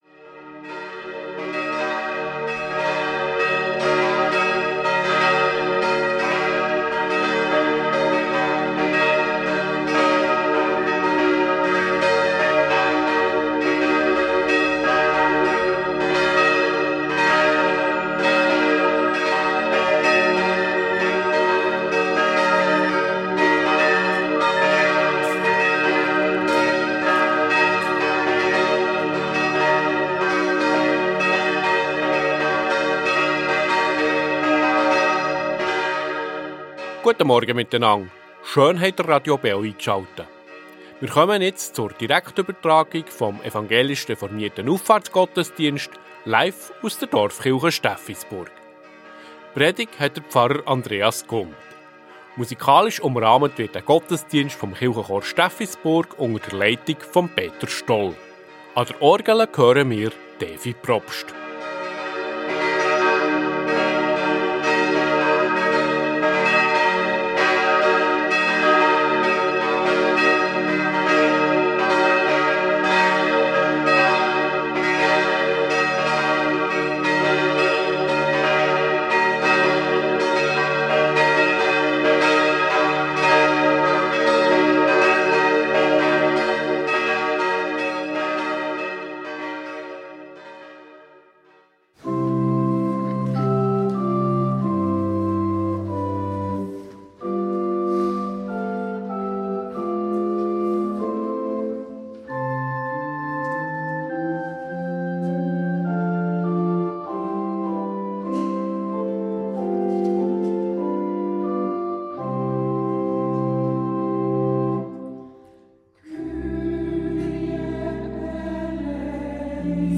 Reformierte Dorfkirche Steffisburg ~ Gottesdienst auf Radio BeO Podcast
Reformierte Dorfkirche Steffisburg